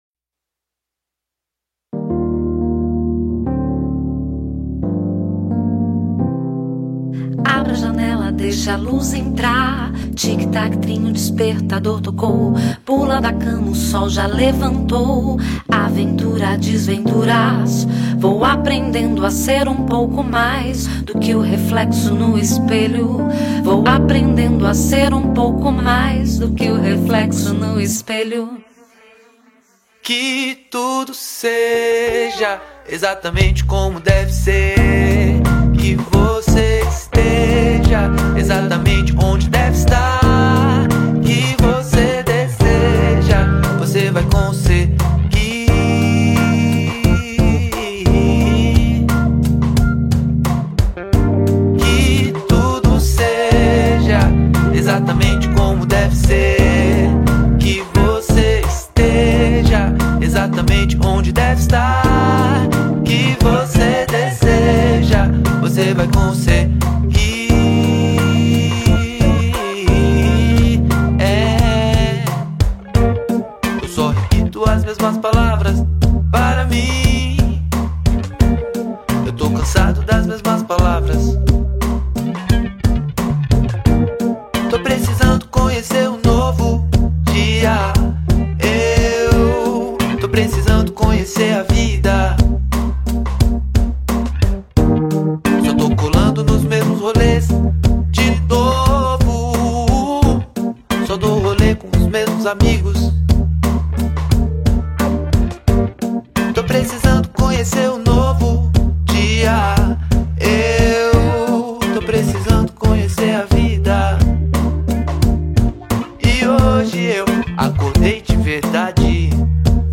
EstiloBlack Music